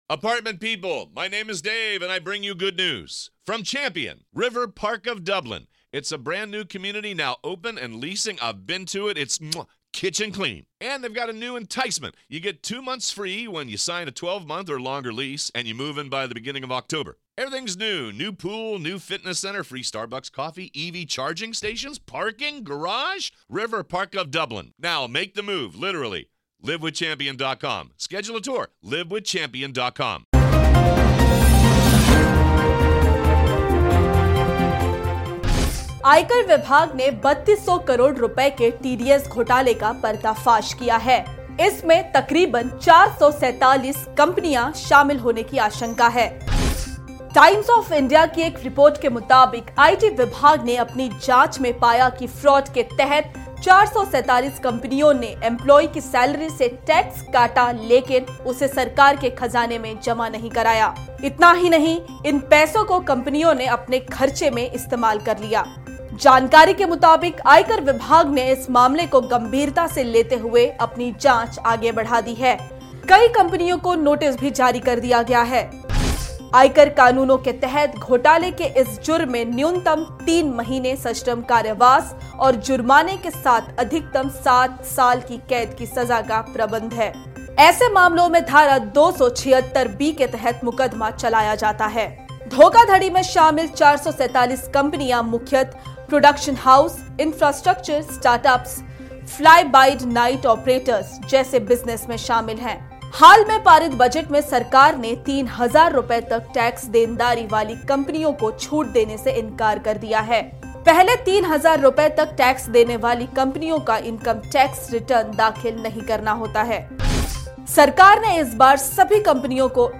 News Report / आयकर विभाग ने किया 3200 करोड़ TDS के घोटाले का पर्दाफाश